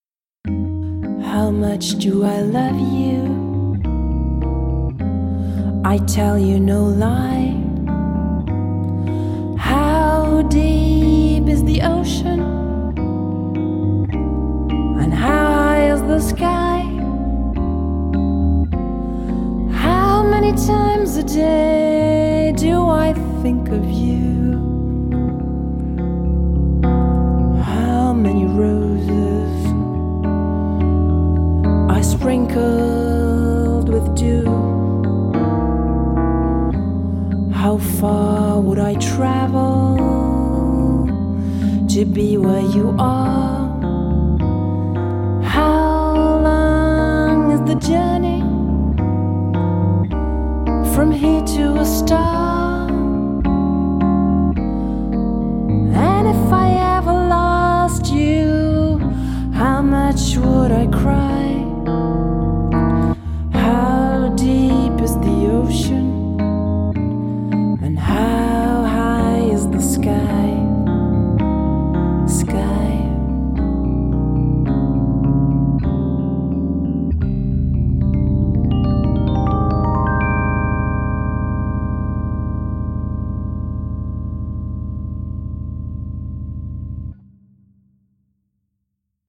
Sängerin und Pianistin